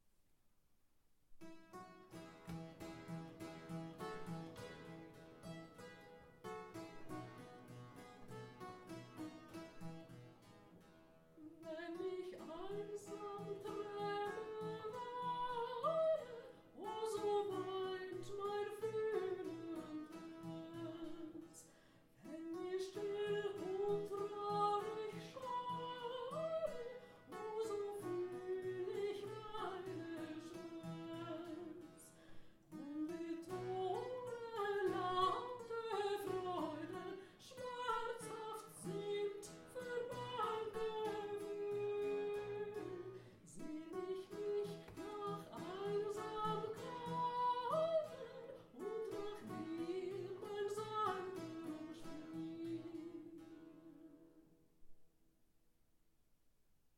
Singing at the Clavichord: Interpretative aspects of repertoire from Brødremenigheden in Christiansfeld
Voice clavichord Moravian music